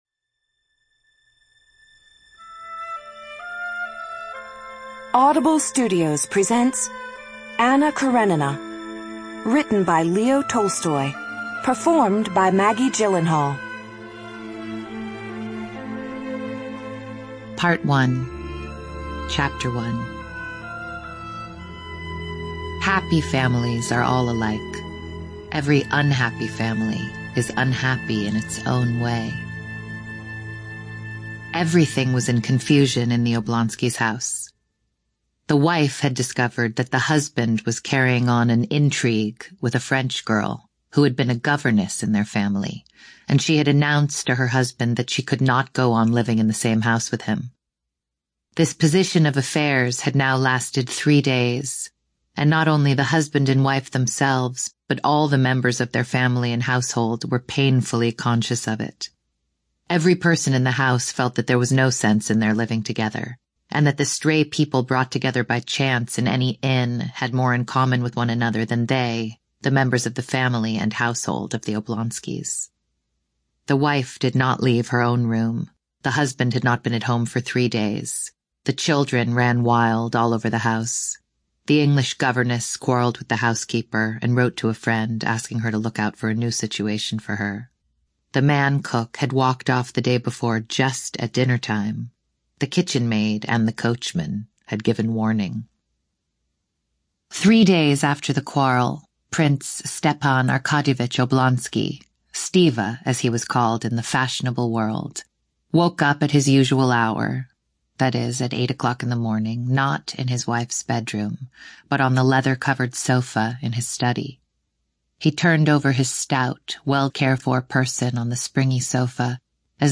ЧитаетДжиллелхаал М.